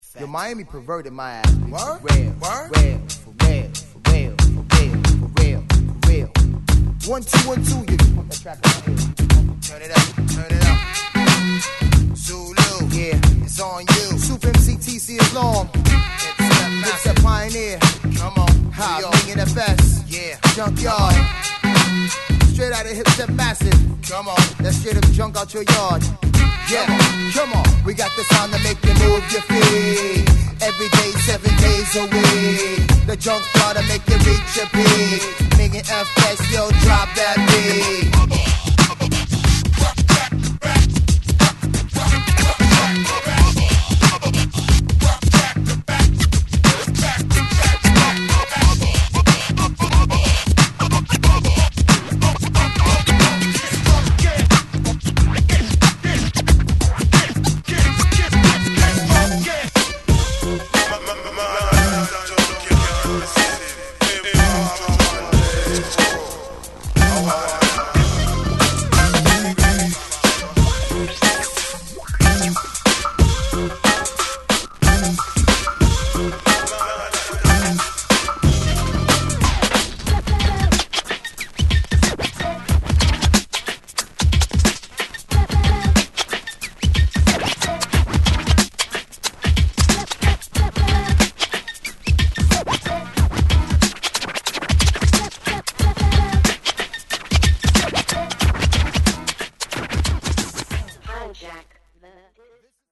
ホーム HIP HOP UNDERGROUND 12' & LP M